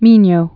(mēnyō)